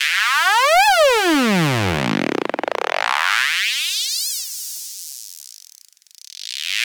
Звуковой эффект (Техно-транс): Mod Schnarrz C
Тут вы можете прослушать онлайн и скачать бесплатно аудио запись из категории «Techno Trance».